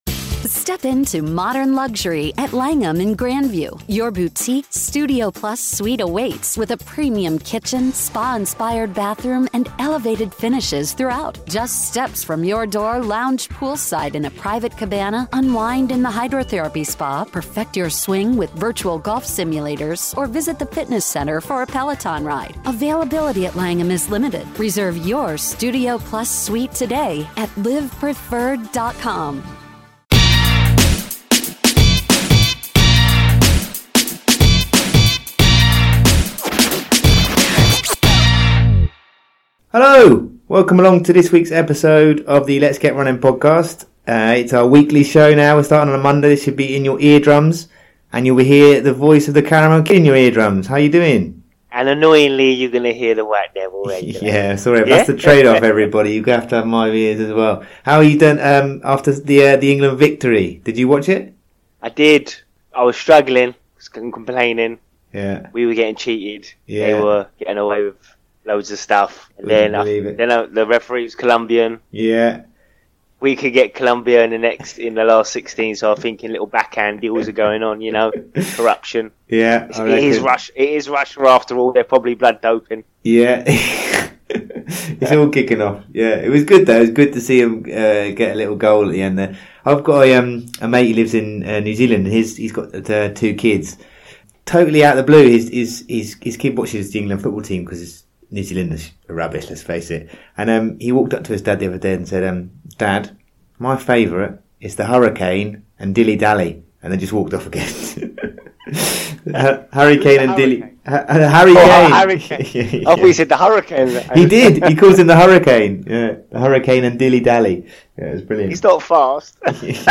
We speak to Journalist and Historian